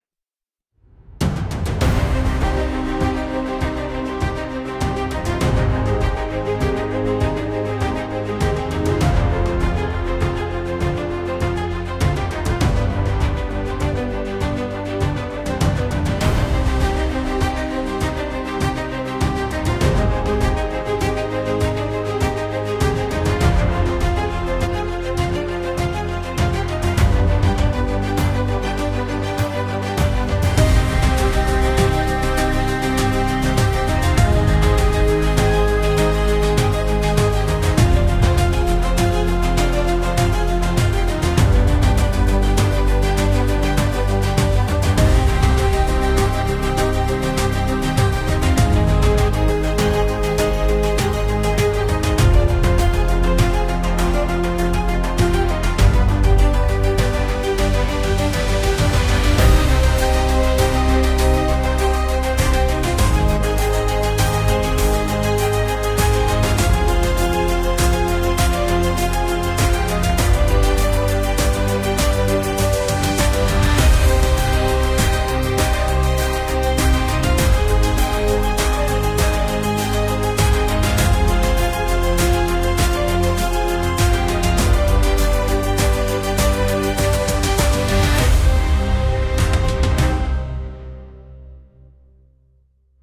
「アドベンチャー映画のオープニング」で生成してみました。